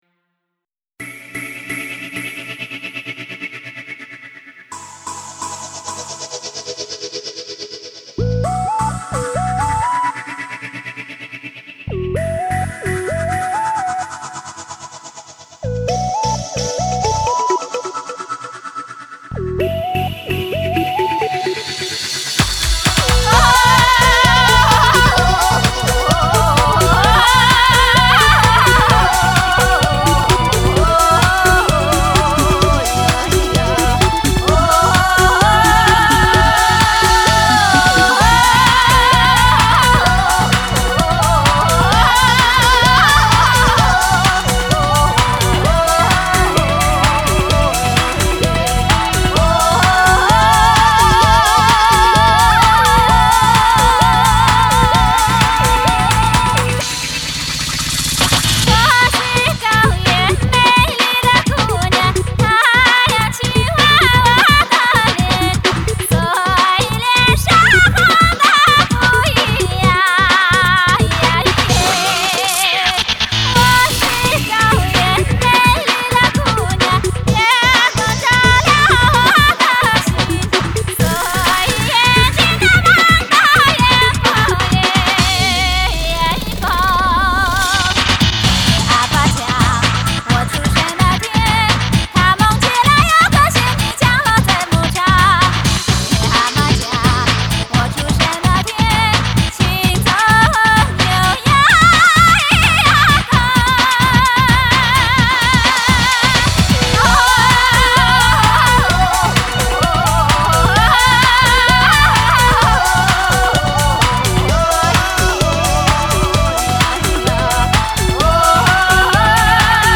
Жанр: Modern Traditional / Chinese pop / Tibetan folk